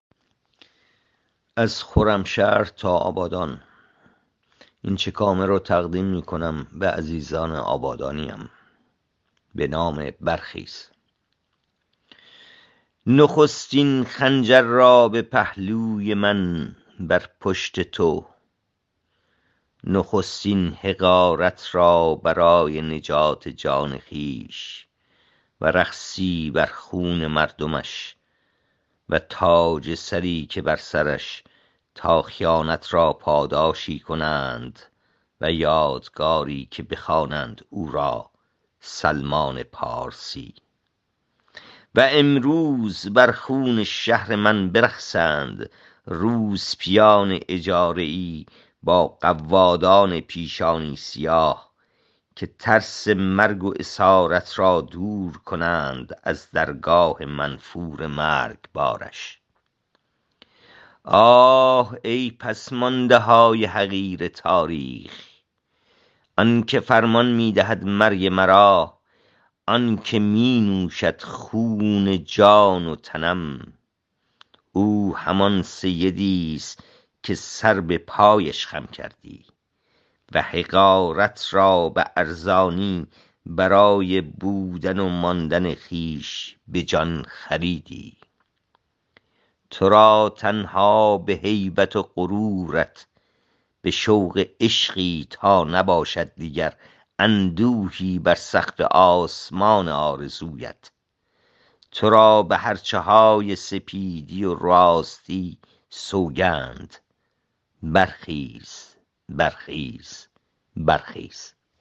این چکامه را با صدای شاعر بشنوید